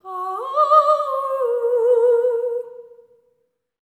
LEGATO 04 -L.wav